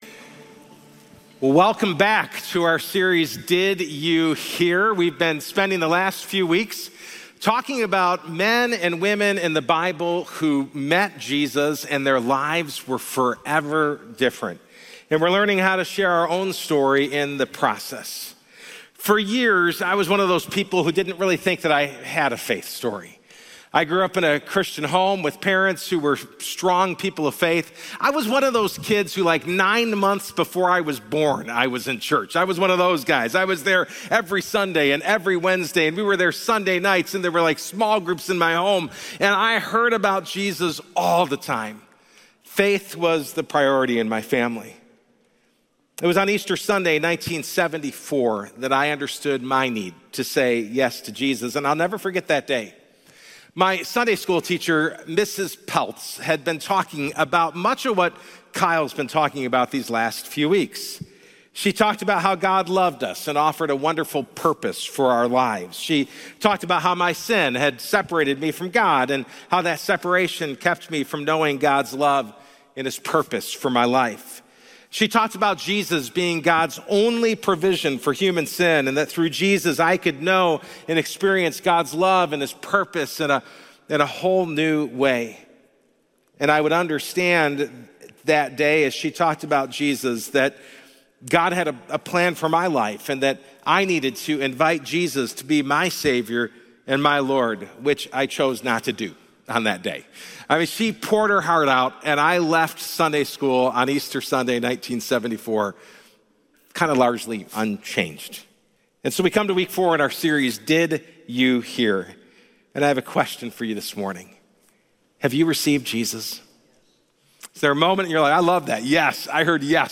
Share this Sermon Facebook Twitter Previous I was Blind, Now I See Next Baptisms & Stories More from Series June 29, 2025 Series: Did You Hear?